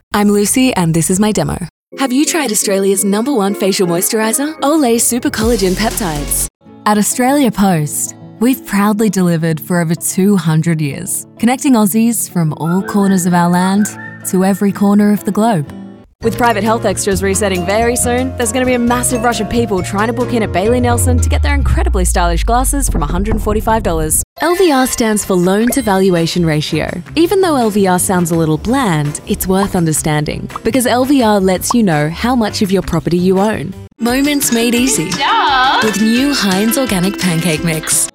Foreign & British Female Voice Over Artists & Actors
Adult (30-50) | Yng Adult (18-29)